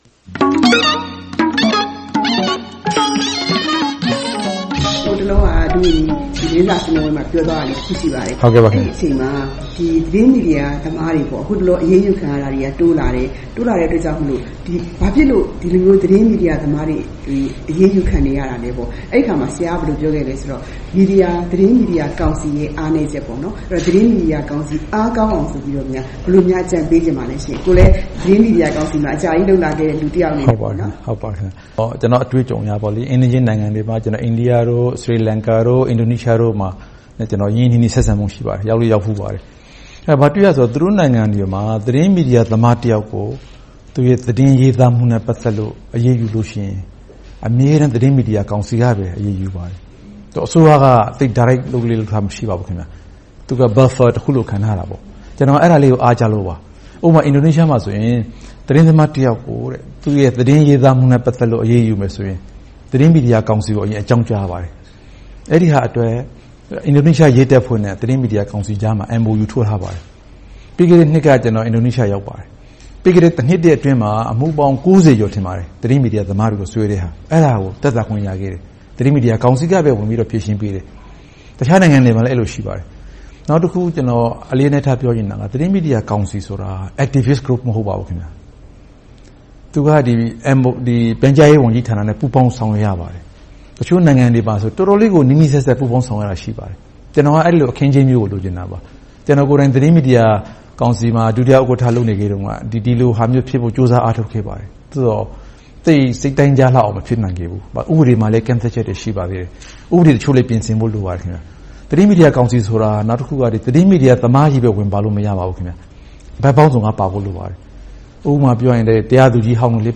သီးသန့်တွေ့ဆုံမေးမြန်းထားပါတယ်။